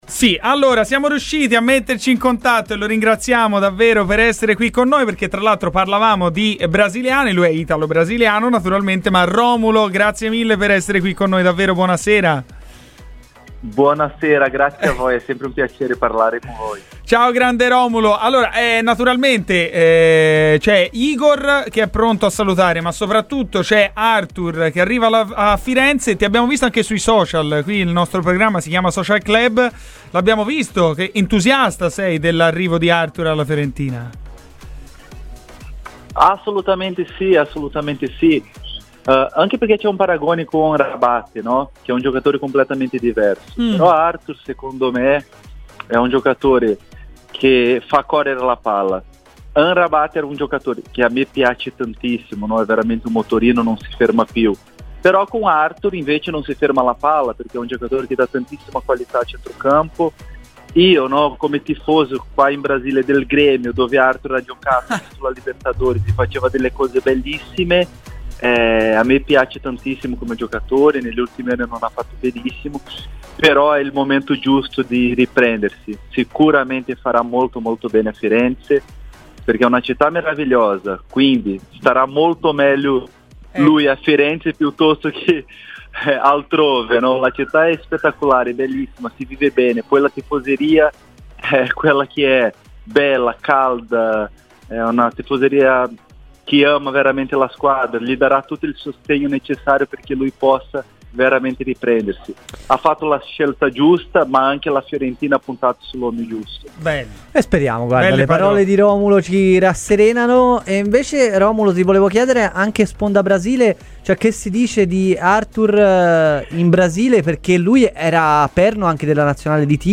L'ex viola Romulo ha parlato ai microfoni di RadioFirenzeViola, nel corso della trasmissione Social Club, dove ha parlato del connazionale Arthur.